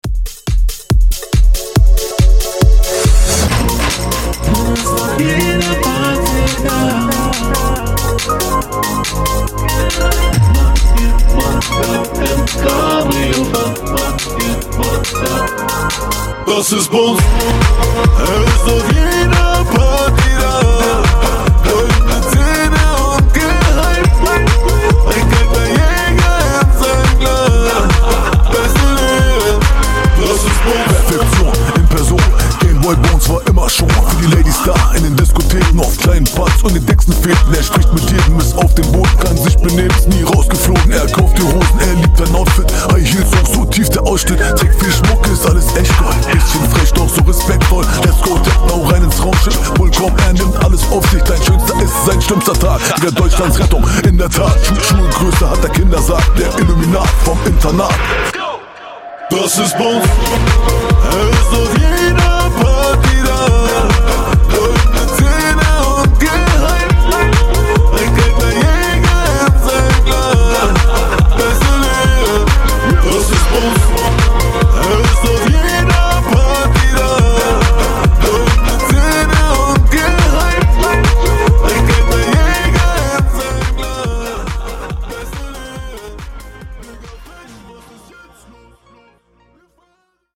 Genre: DANCE Version: Clean BPM: 140 Time